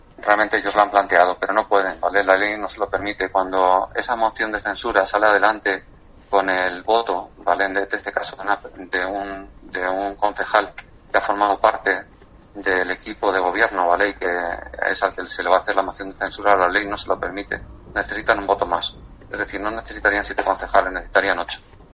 En declaraciones a Cope el alcalde de Las Navas ha afirmado que tanto PP, como Vox y la concejala adscrita han intentado una moción de censura, si bien la ley no permite que salga adelante.